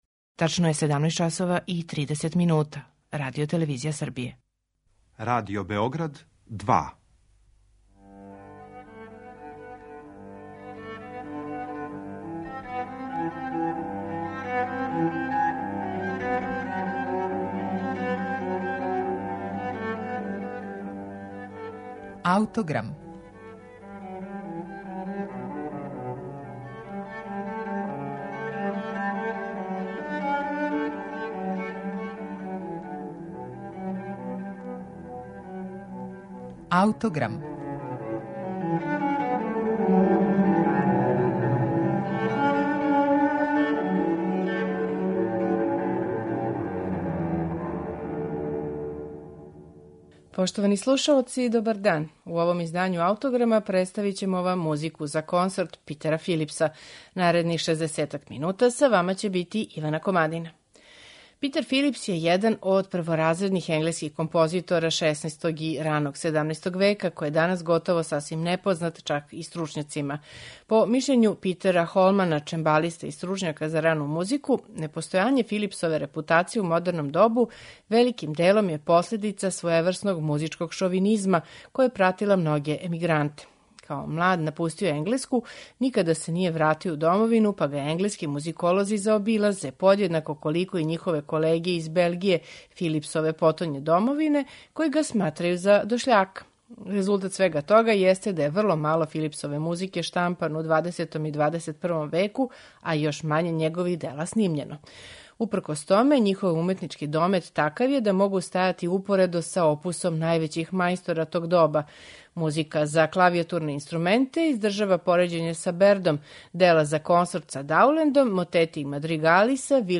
У данашњем Аутограму, представићемо Музику за консорт Питера Филипса, у интерпретацији ансамбла The Parley of instruments.